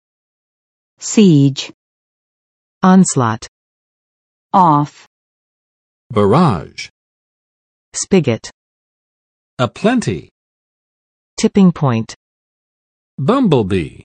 [sidʒ] n. 围攻；包围；围城